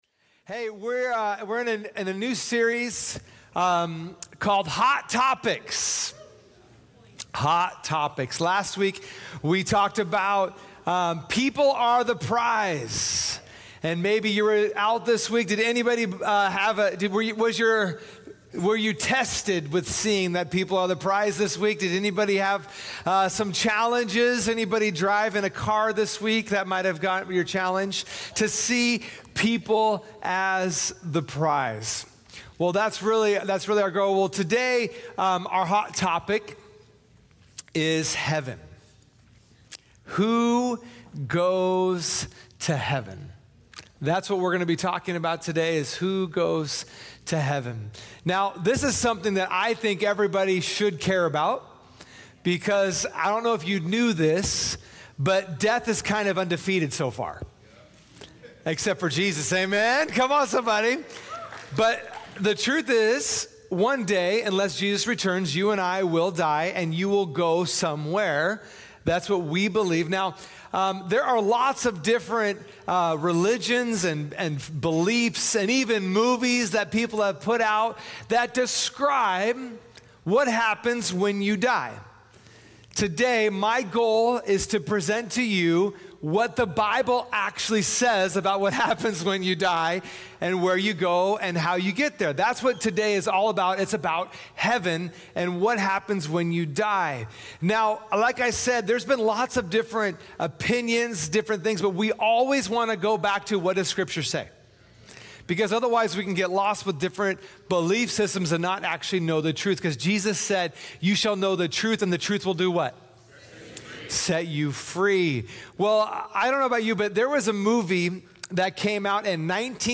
Sunday Messages from Portland Christian Center "Who Goes to Heaven?"